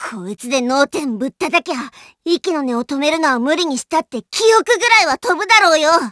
Worms speechbanks
coward.wav